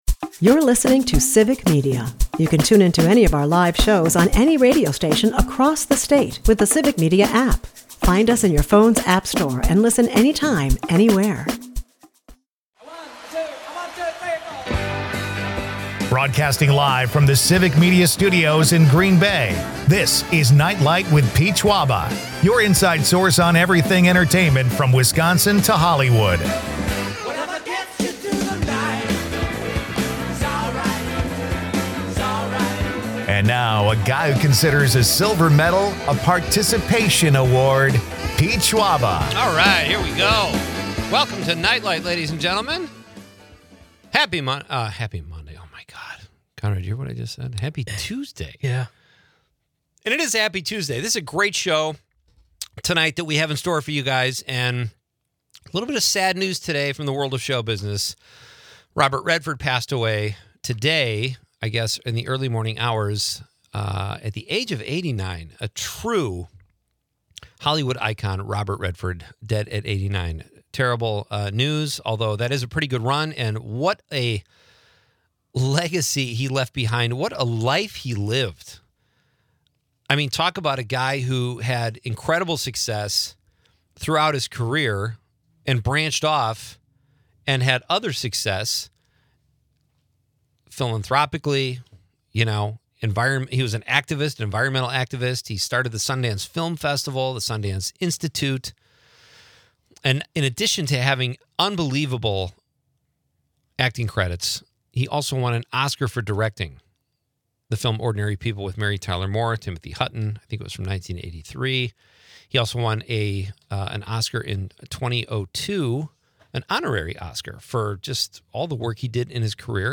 The episode delves into his iconic roles in films like 'The Sting,' 'Butch Cassidy and the Sundance Kid,' and 'All the President's Men,' while listeners share their favorite Redford films.